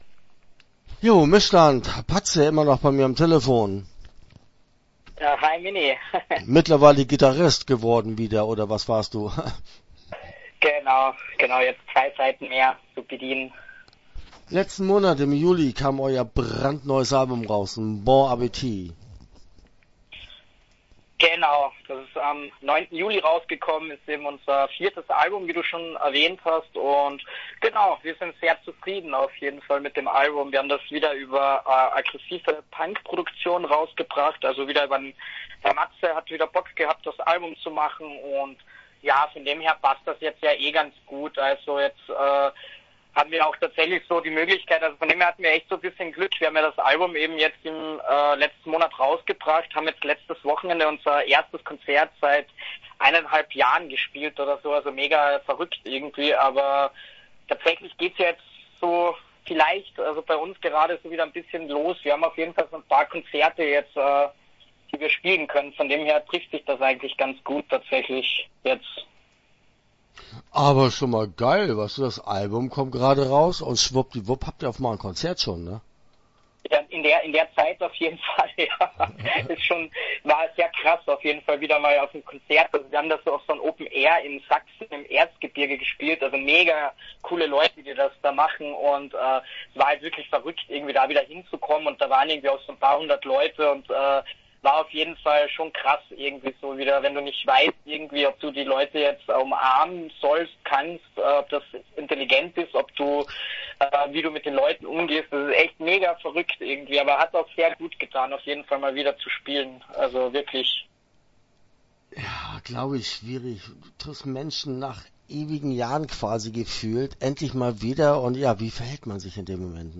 Start » Interviews » MISSSTAND